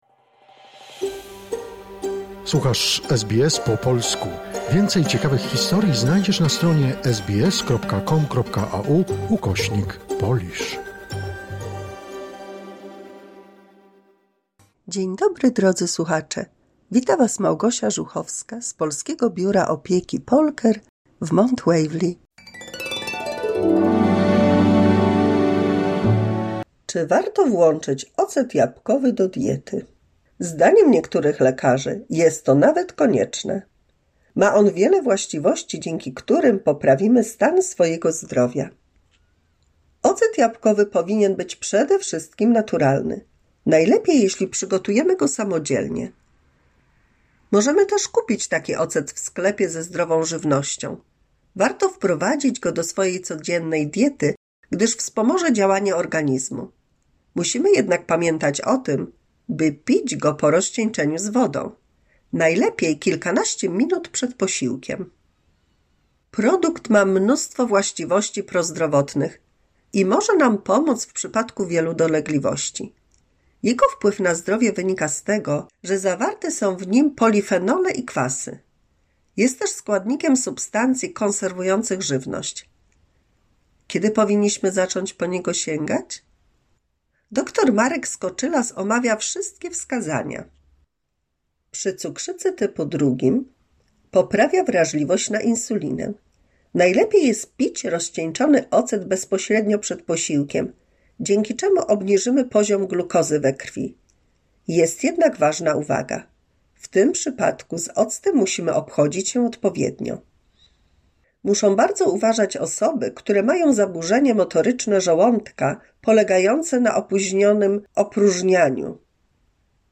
176 mini słuchowisko dla polskich seniorów